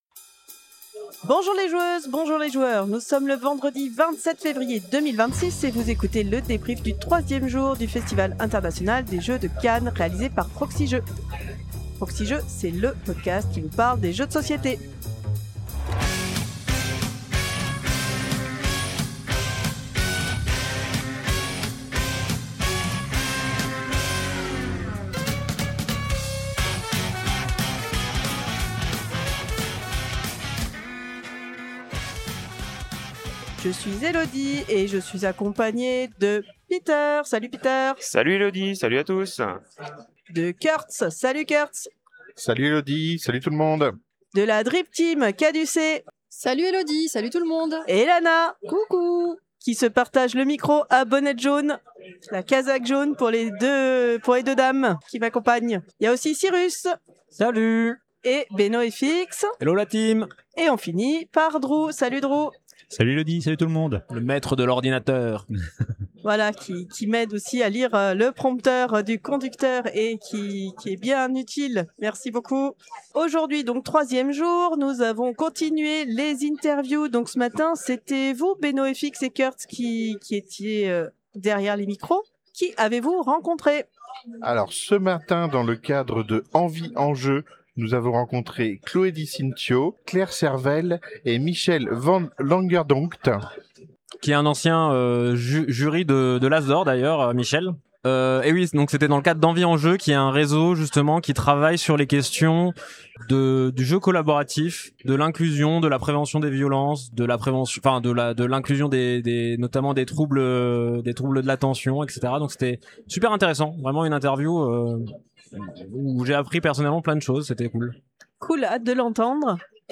FIJ 2026, vendredi, écoutez le ressenti à chaud de la délégation de Proxi-Jeux présente au Festival International des Jeux de Cannes 2026.
Vendredi, troisième jour du FIJ 2026 ! Des interviews et des jeux !